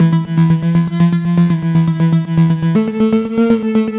Index of /musicradar/french-house-chillout-samples/120bpm/Instruments
FHC_Arp B_120-E.wav